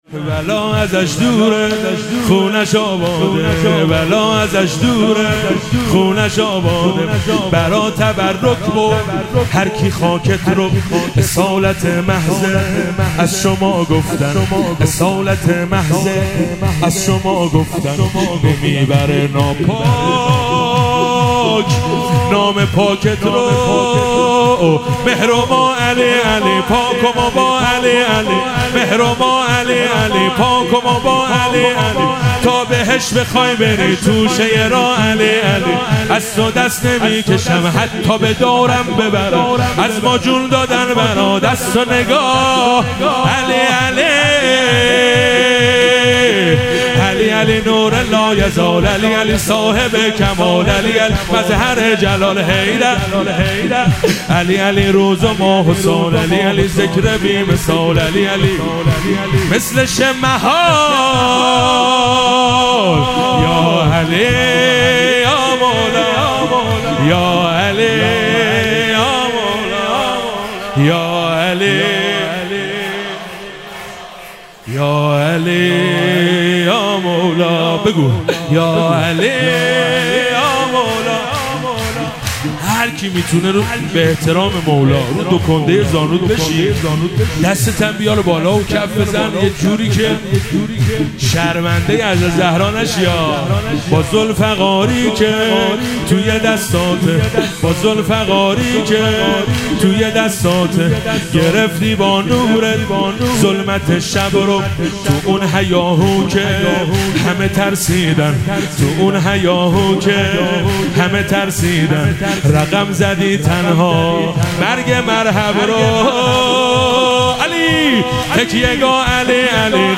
مراسم جشن شب سوم ویژه برنامه عید سعید غدیر خم 1444
شور- بلا ازش دوره خونه اش آباده